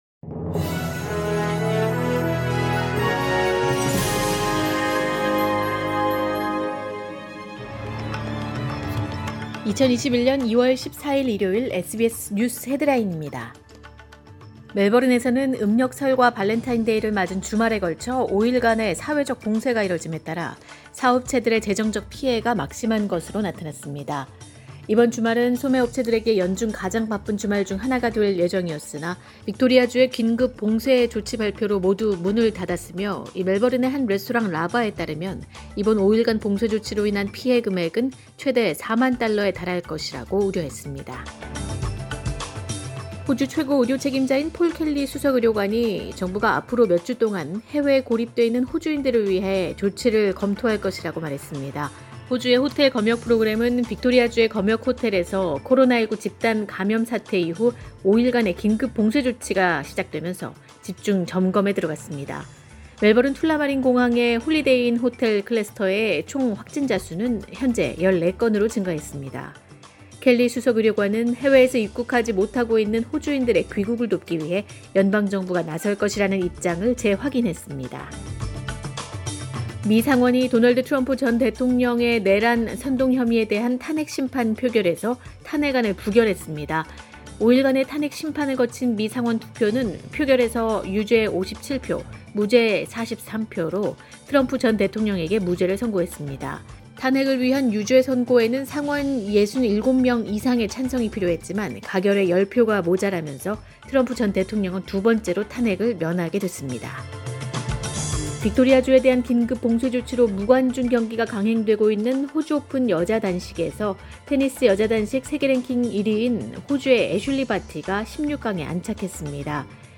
2021년 2월 14일 일요일 SBS 뉴스 헤드라인입니다.